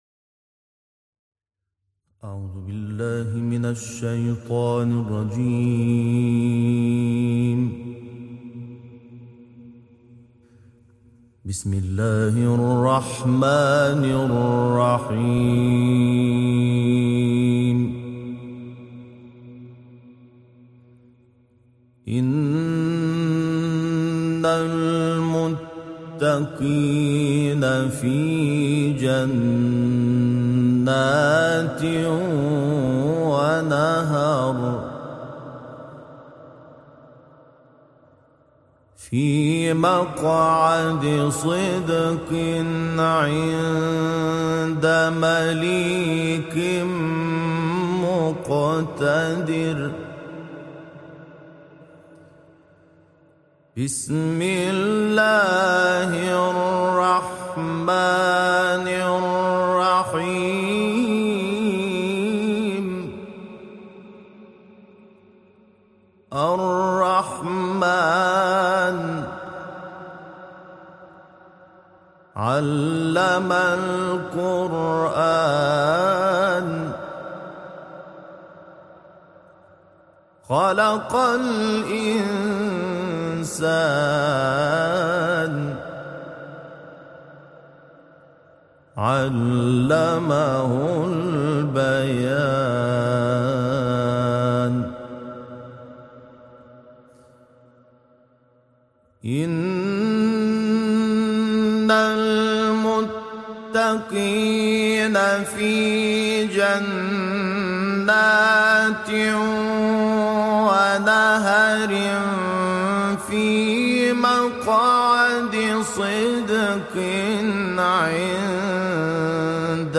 گروه چندرسانه‌ای ــ تلاوت آیات پایانی سوره مبارکه قمر، آیات 1 تا 28 سوره مبارکه الرحمن، آیات ابتدایی سوره ملک، آیات پایانی فجر و سوره‌های مبارکه شمس، فلق، ناس، حمد و آیات ابتدایی سوره بقره با صدای محمود شحات انور ارائه می‌شود. این تلاوت استودیویی کمتر شنیده شده در استودیویی در کشور کویت ضبط شده است.